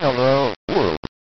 音声(元は11kHz/8bitモノラル）
2.元ファイル→2bitDPCM
voice_dpcm.mp3